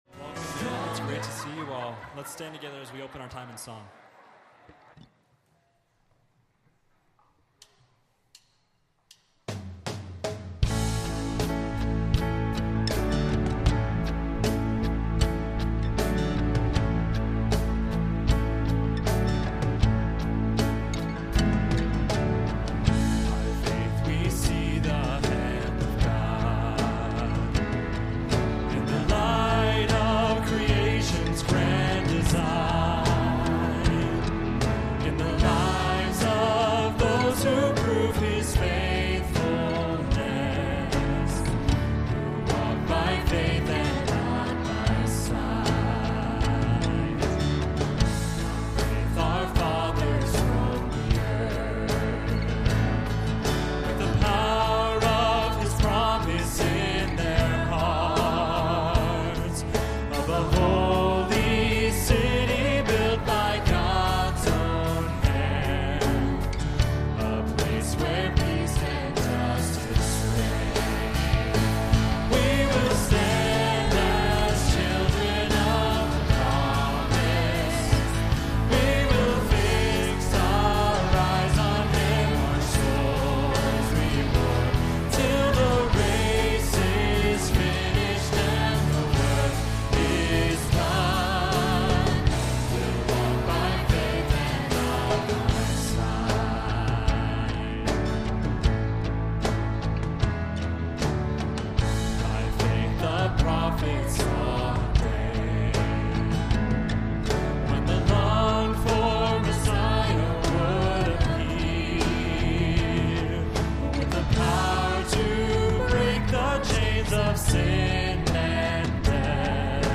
Evening Worship Service